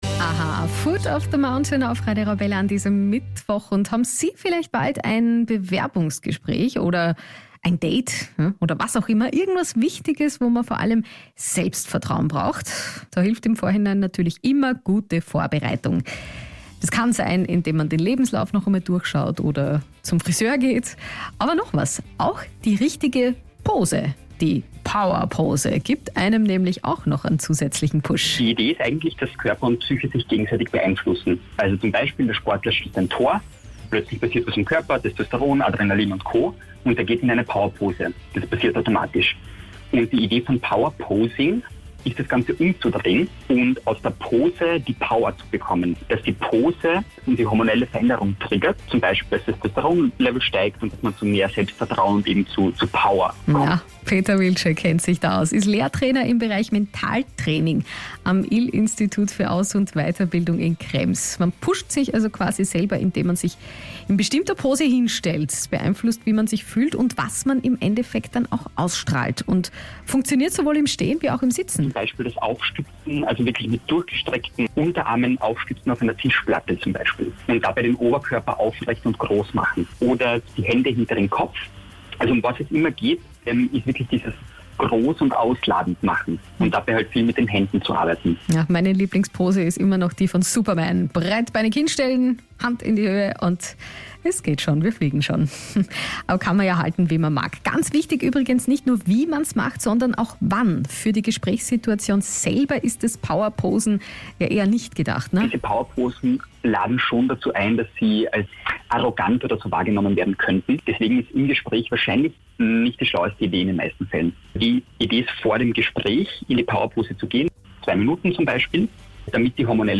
Im Interview mit Radio Arabella – Wie Power Posing dir zum Erfolg verhilft